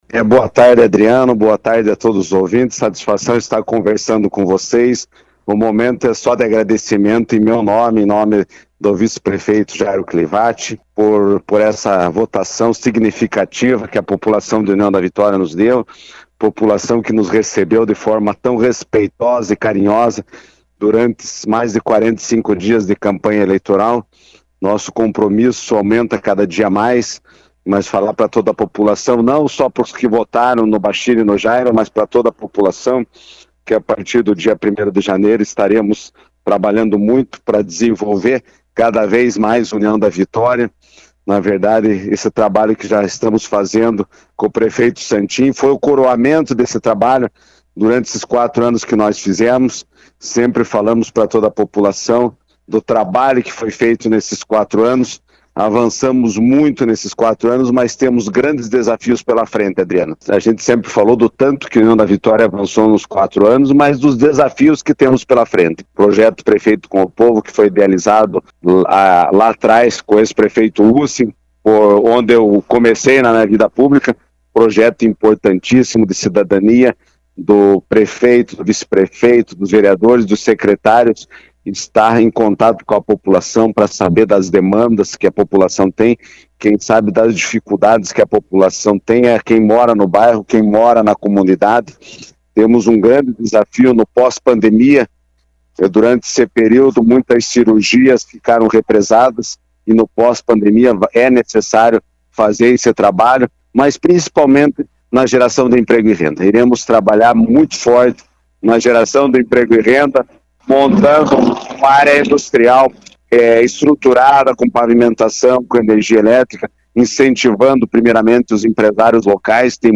Em entrevista ao Jornal Colmeia nessa segunda-feira, 16 de novembro, Abbas agradeceu novamente a população que o elegeu e a sua equipe que o auxiliou durante mais de 45 dias de campanha.